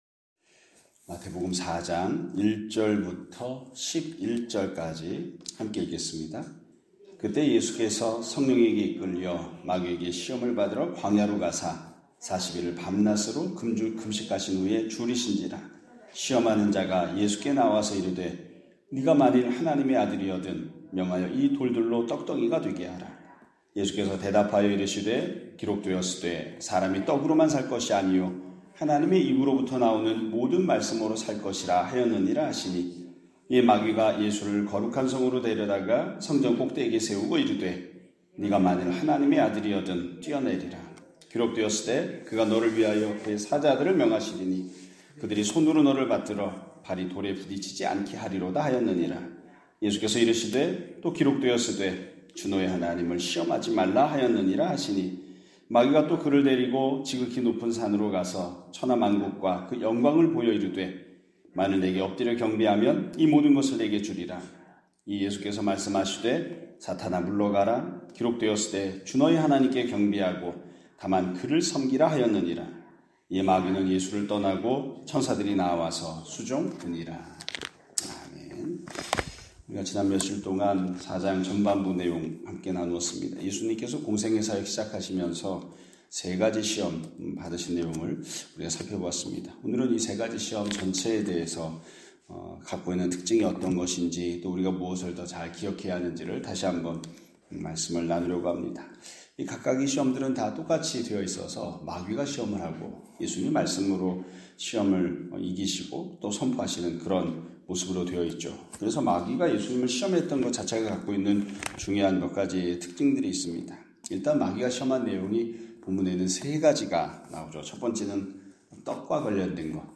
2025년 4월 29일(화요일) <아침예배> 설교입니다.